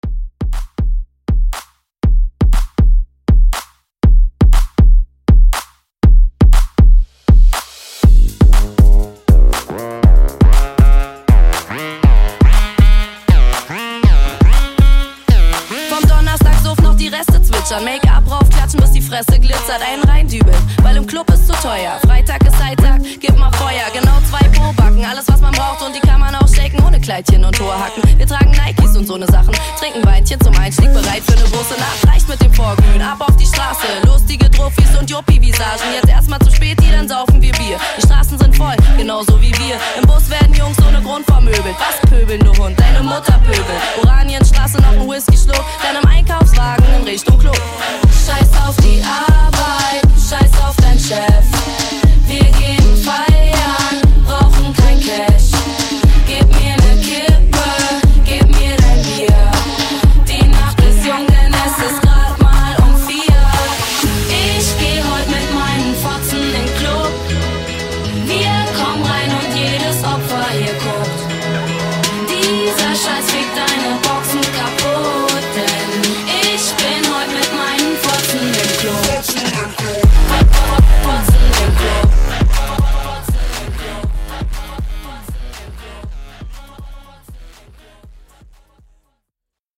Genres: HIPHOP , RE-DRUM , TOP40
Dirty BPM: 93 Time